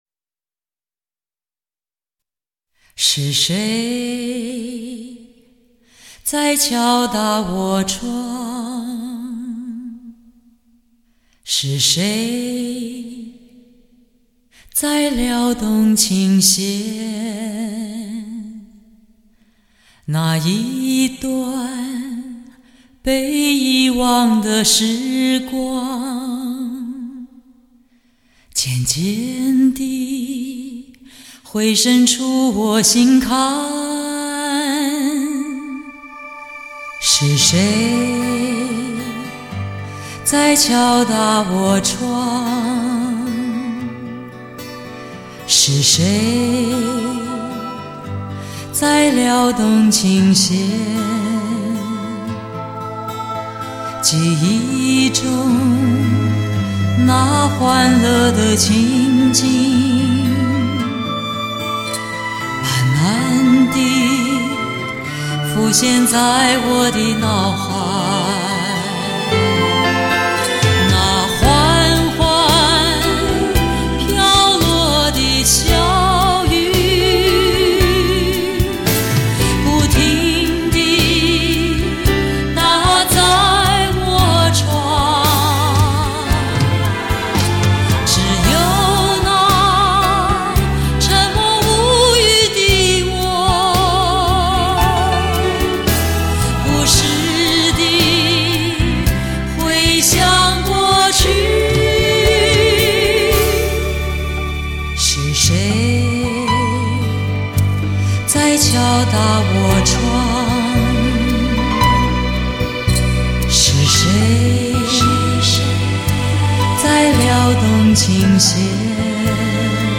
震天动地的强劲动感 举座皆惊的靓绝旋律